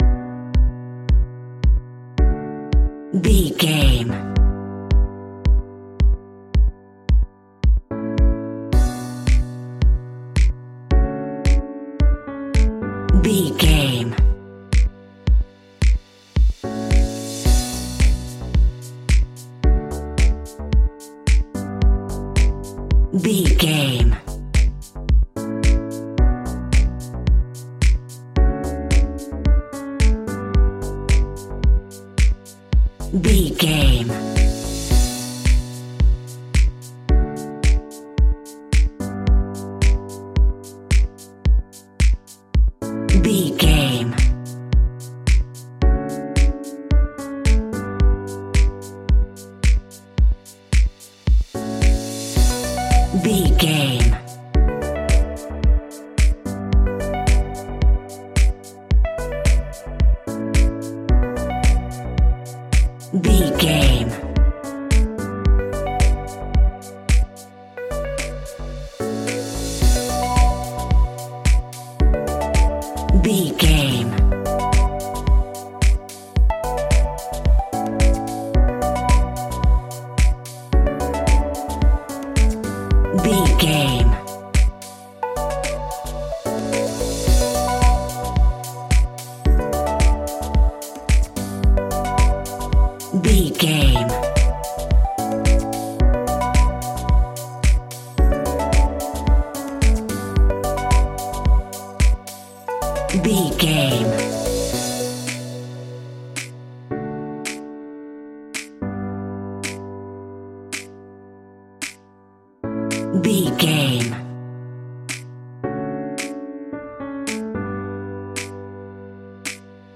Aeolian/Minor
groovy
dreamy
smooth
futuristic
drum machine
synthesiser
electro house
funky house
synth leads
synth bass